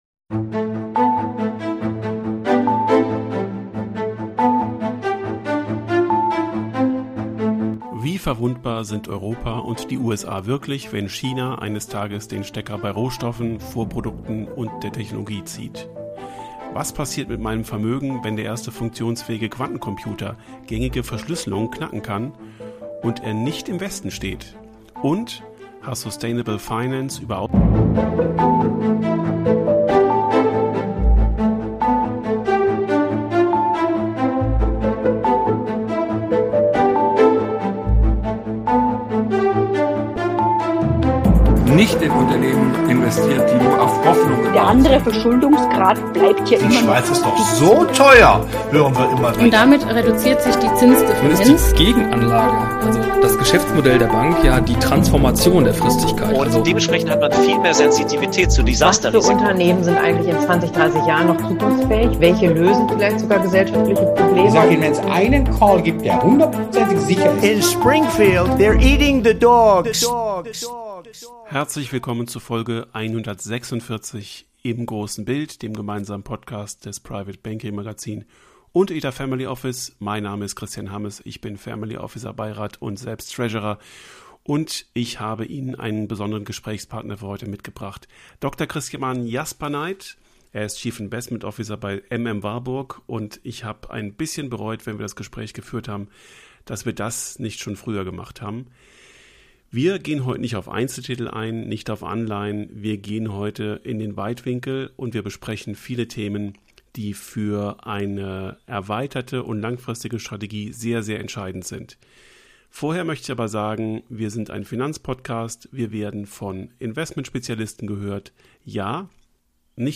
Im Gespräch
aufgezeichnet am Rande des private banking kongress' in Hamburg